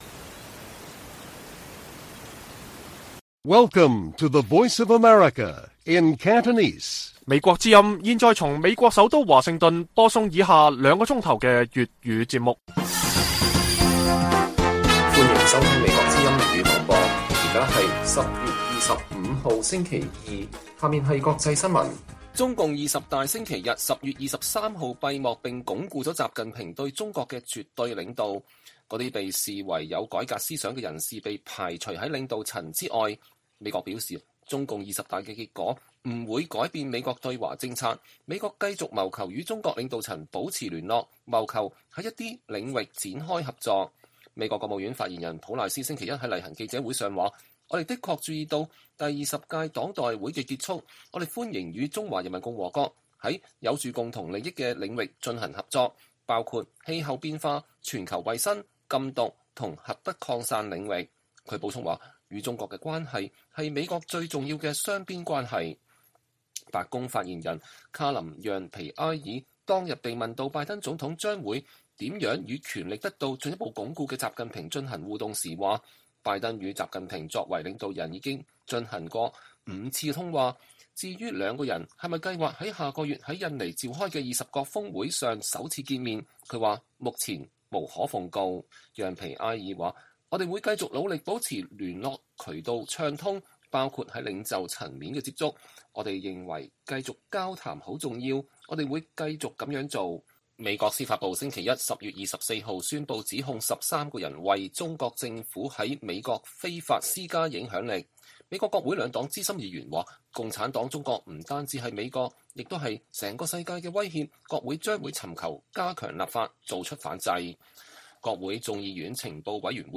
粵語新聞 晚上9-10點：中共20大閉幕鞏固習近平絕對領導權 美國稱繼續謀求與北京對話合作